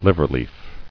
[liv·er·leaf]